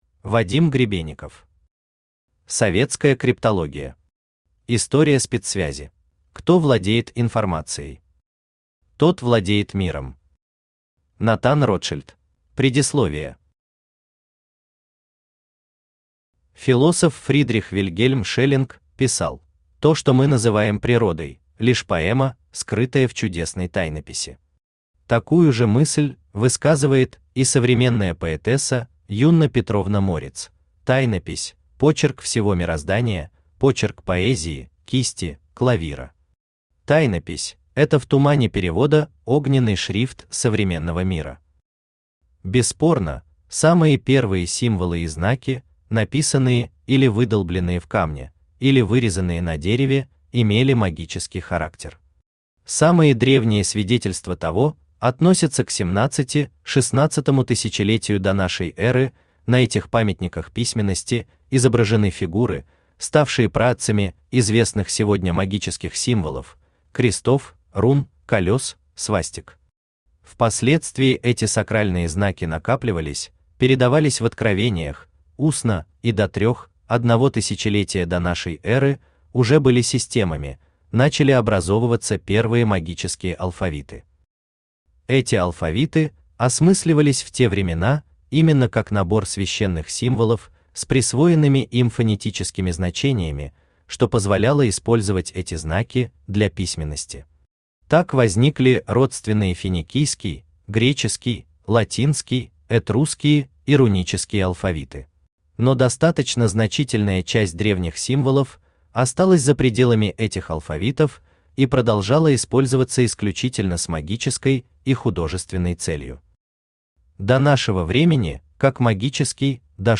Аудиокнига Советская криптология. История спецсвязи | Библиотека аудиокниг
История спецсвязи Автор Вадим Гребенников Читает аудиокнигу Авточтец ЛитРес.